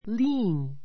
lean 1 líːn り ーン 形容詞 やせた He is tall and lean, and his wife is small and fat.